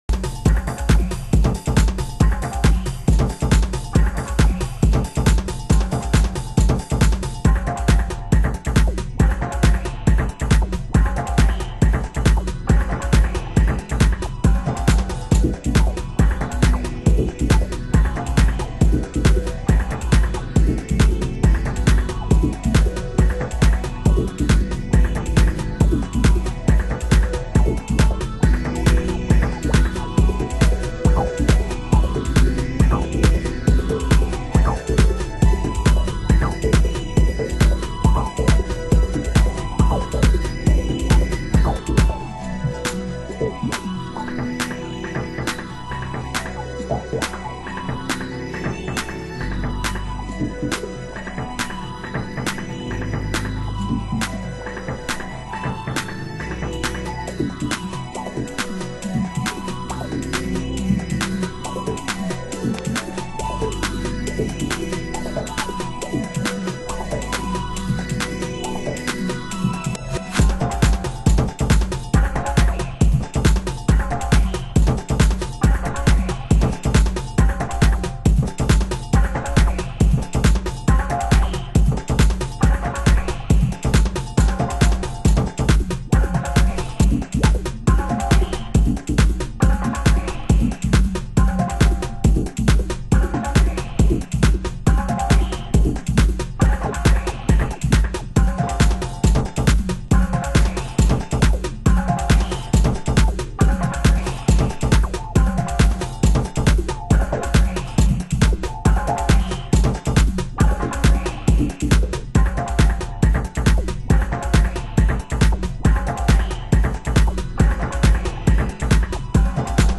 盤質：盤面良好ですが、少しチリパチノイズ有